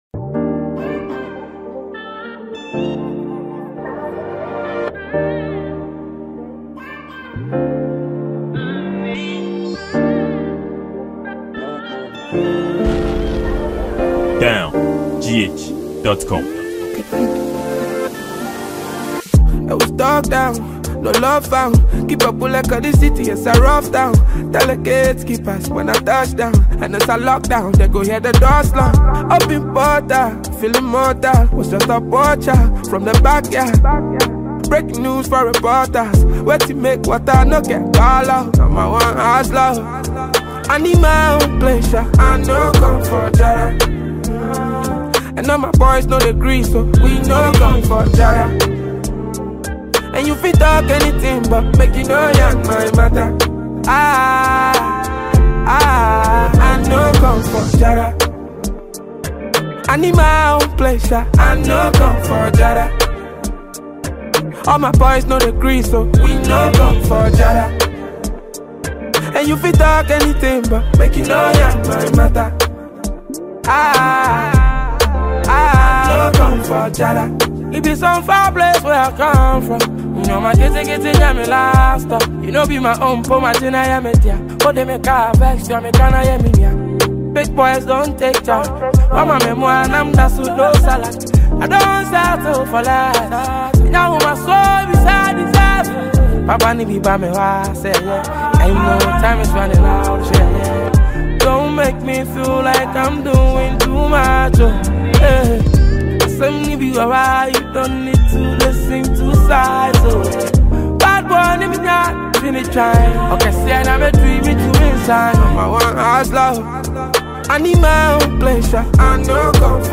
Ghanaian award winning songwriter and highlife singer